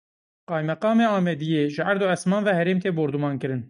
/hɛˈɾeːm/